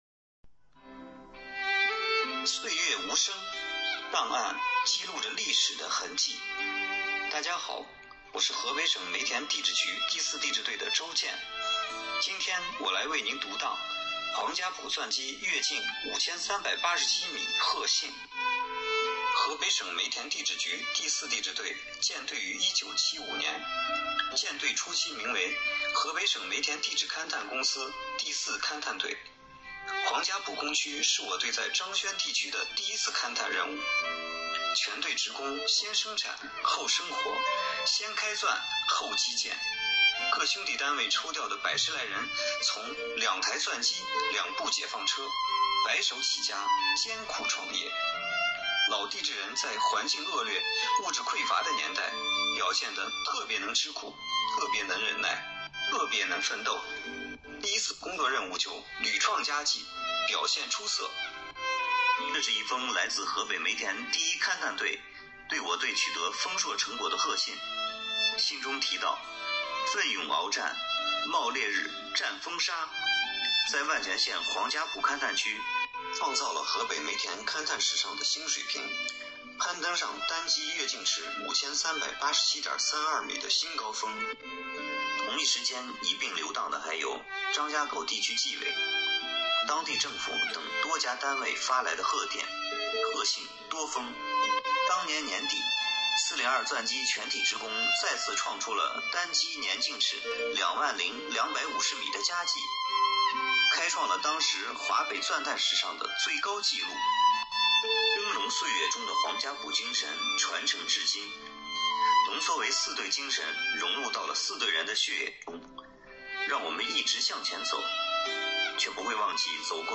此次活动是由京津冀档案工作者讲述本单位珍藏档案及背后的故事。我队档案室积极组织人员参与，深入挖掘队存档案资源，遴选出展现四队历史风貌的内容，精心打磨读档文稿，全面把关配乐录制，高质量完成申报作品。获奖作品讲述了1976年四队第一次在万全县黄家堡勘探区执行工作任务就屡创佳绩的光荣历史，体现了四队人“三光荣”“四特别”的宝贵精神。